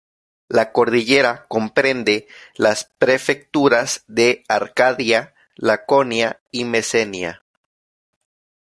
cor‧di‧lle‧ra
/koɾdiˈʝeɾa/